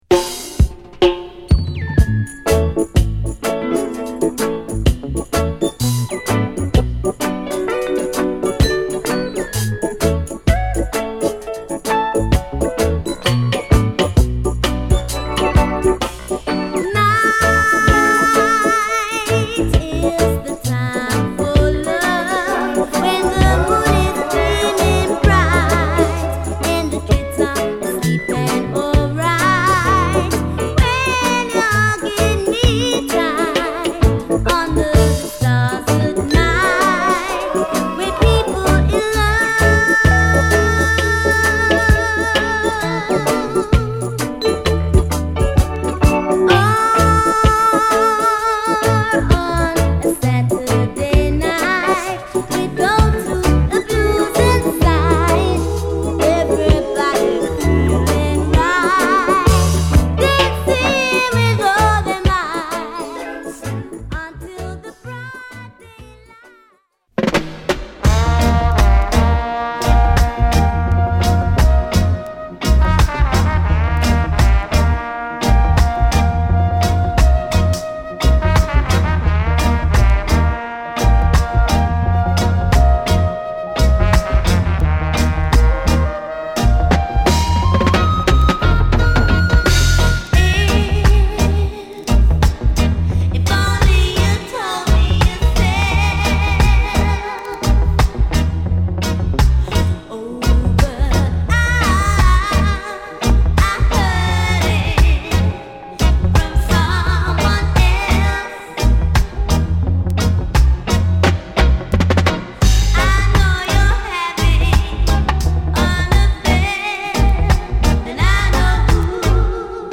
シンプルでタフなプロダクション！アルバム通して極上の心地よさを漂わせる名作！